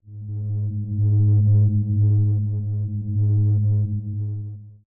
Ambient1.ogg